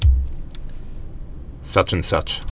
(sŭchən-sŭch)